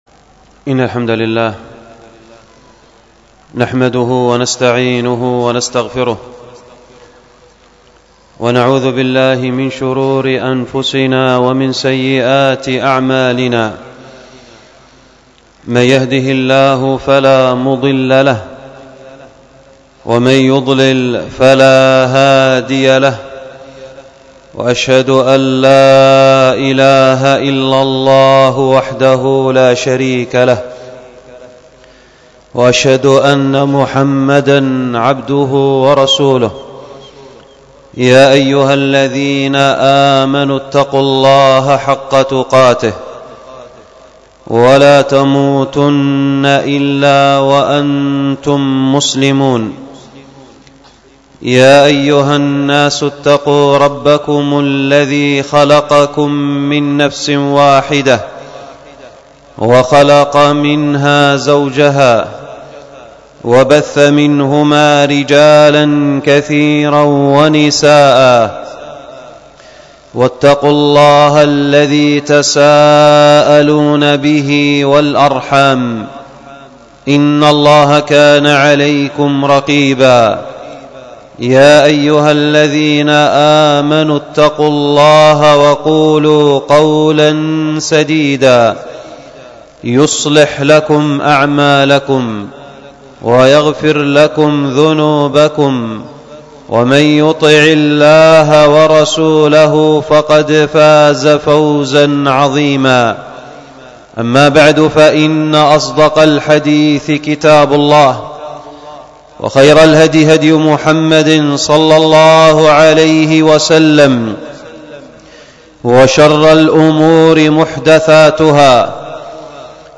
الدرس في شرح الرائد في علم الفرائض 1، ألقاها